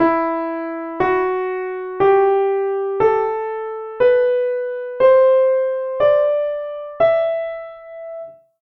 E natural minor
E_Natural_Minor_ascending.mp3